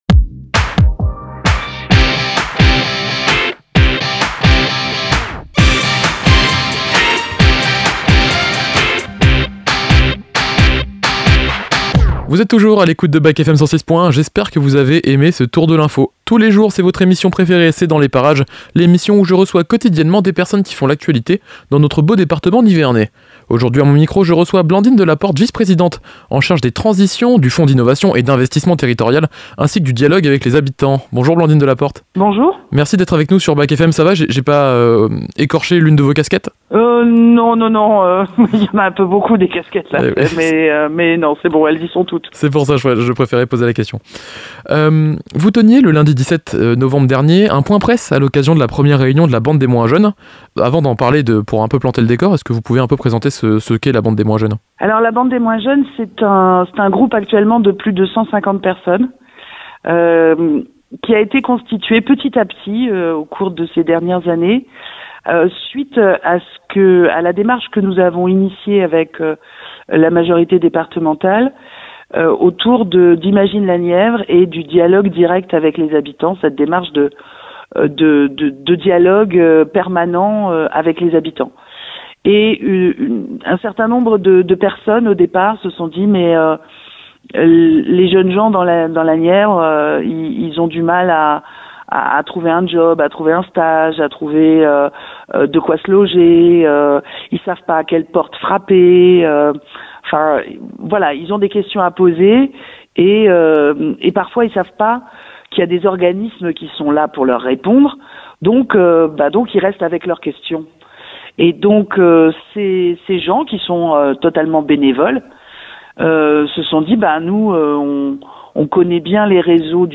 reçoit Blandine Delaporte, vice-présidente du Conseil Départemental de la Nièvre